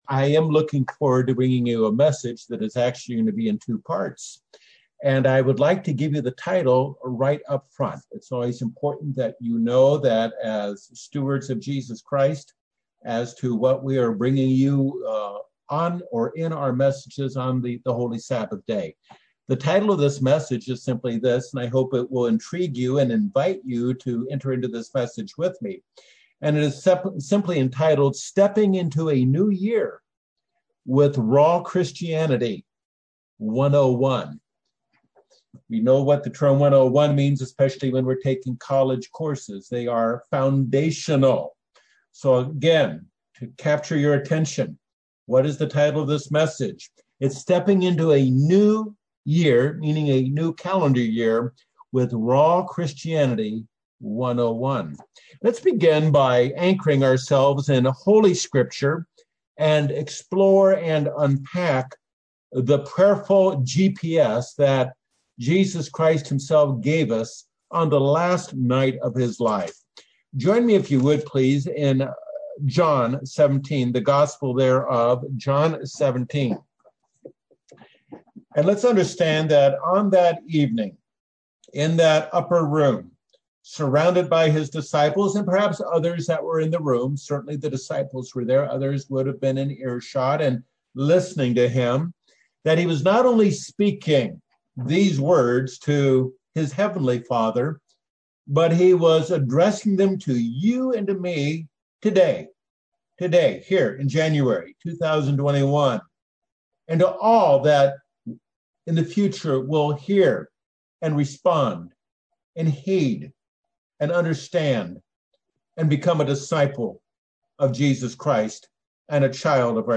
This message (first of a two-part series) focuses on our role in growing in our calling towards fulfilling Jesus' prayer in John 17 that we might be united with God and one another in seamless sacred oneness. The series centers on Ephesians 4:1-6.